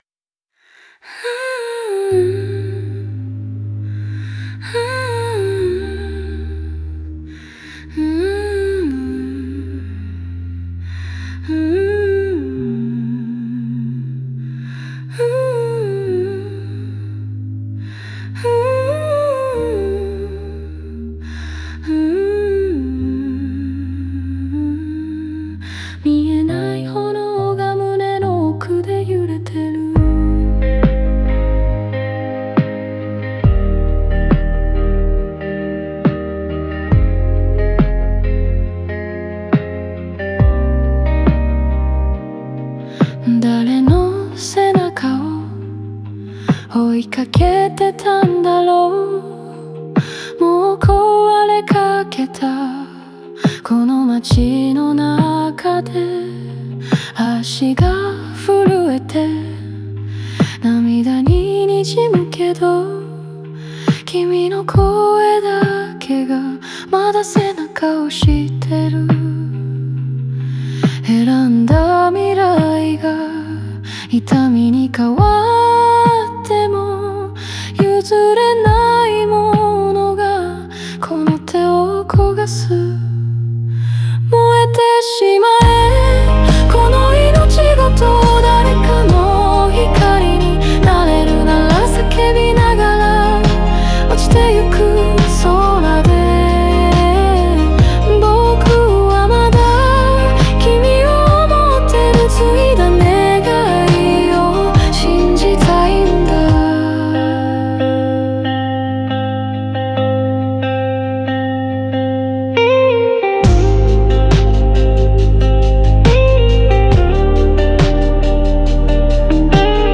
オリジナル曲♪
静寂を経て再度盛り上がる構成は、物語の転換点を象徴し、主人公の成長と覚悟を音楽的に表現しています。